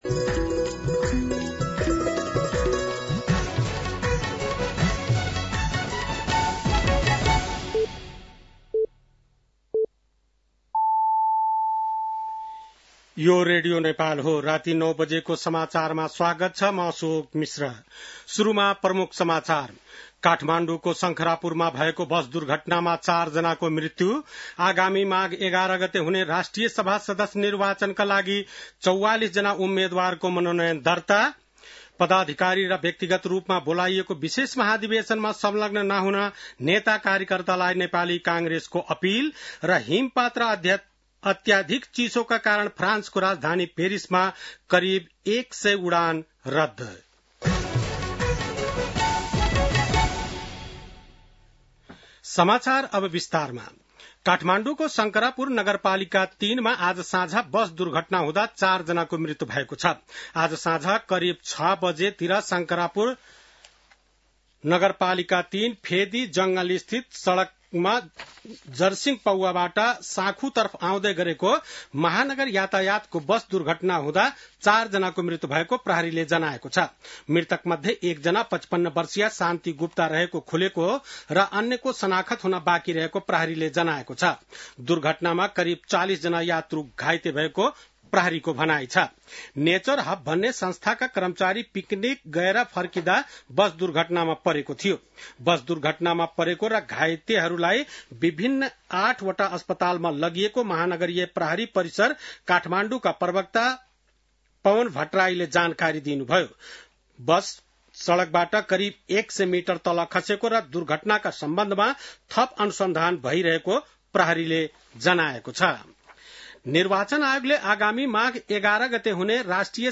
बेलुकी ९ बजेको नेपाली समाचार : २३ पुष , २०८२
9-PM-Nepali-NEWS-09-23.mp3